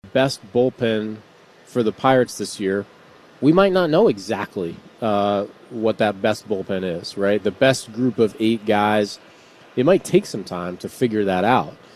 On The Ben Cherington Show on WCCS yesterday, the Pirates’ general manager said the bullpen will be a work in progress this season.